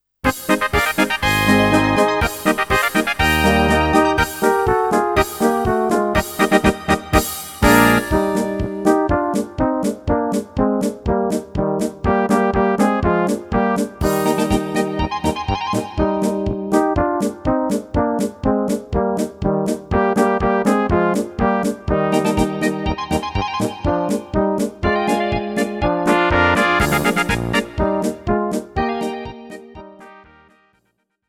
Rubrika: Národní, lidové, dechovka
- polka
Karaoke